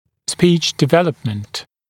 [spiːʧ dɪ’veləpmənt][спи:ч ди’вэлэпмэнт]развитие речи